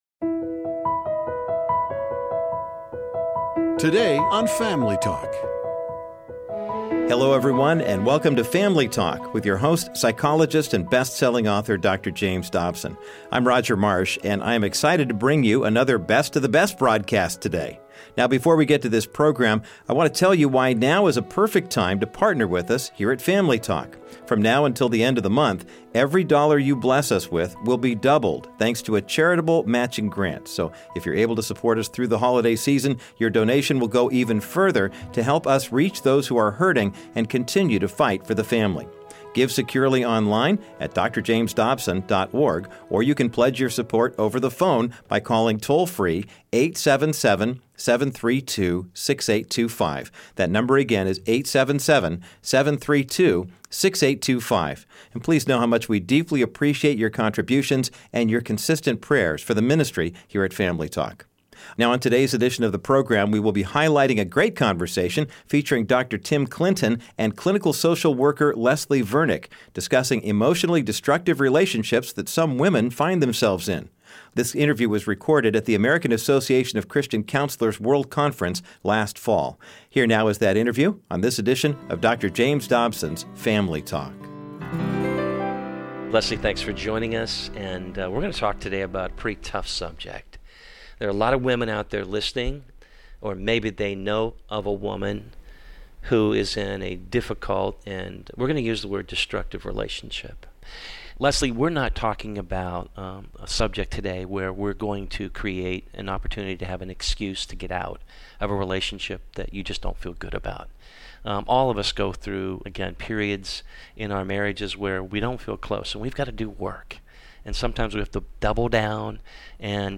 interview
conducted at the AACC World Conference